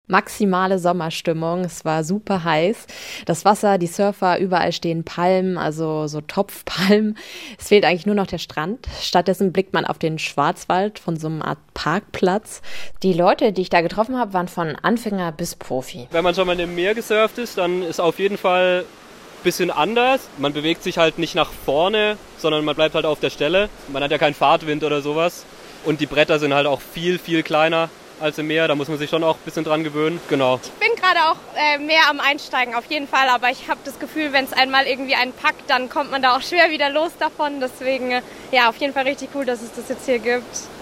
Der Blick beim Surfen in den Schwarzwald war zumindest schon mal besonders, erzählt sie in der SWR3 Morningshow.